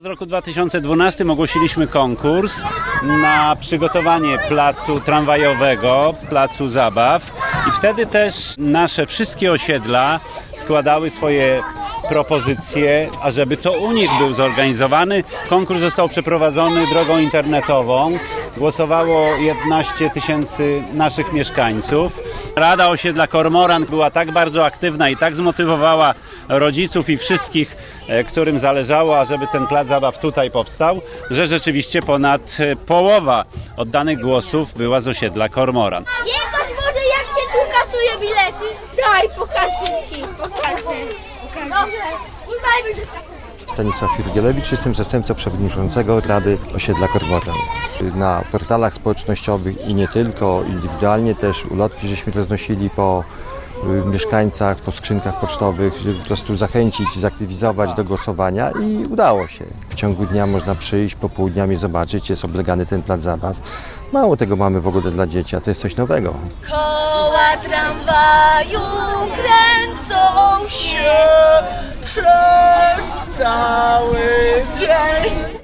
Otwarciu placu towarzyszył gwar i okrzyki najmłodszych dzieci.